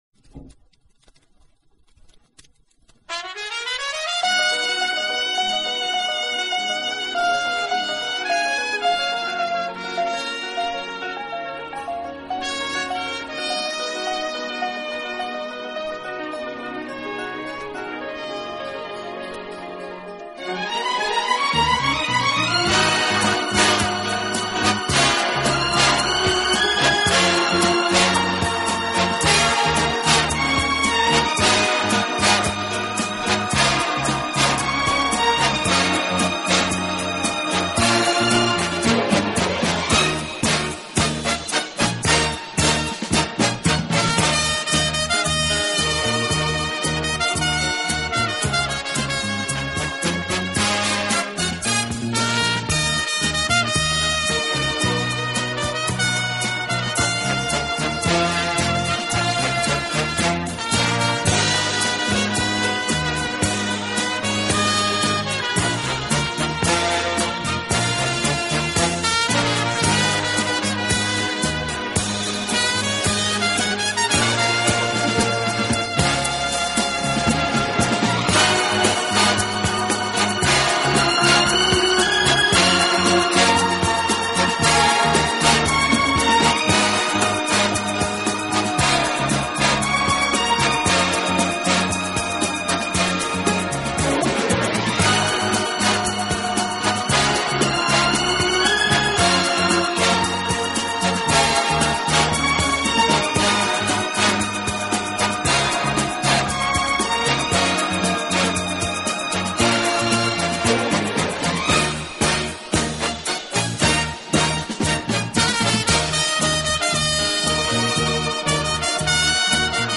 轻音乐专辑
温情、柔软、浪漫是他的特色，也是他与德国众艺术家不同的地方。